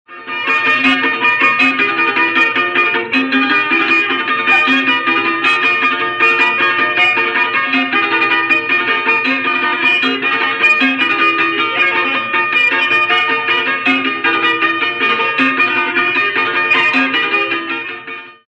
Banjo
Instrumento de corda cujo som é obtido pelo tanger das cordas, que podem ser em número de quatro a seis.
banjo.mp3